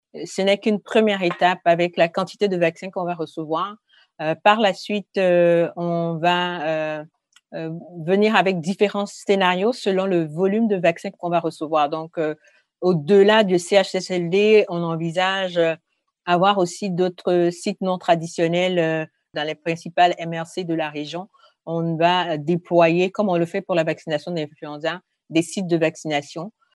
Écouter Marie-Josée Godi, directrice régionale de la santé publique :